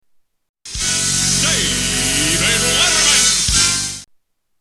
TV Theme Songs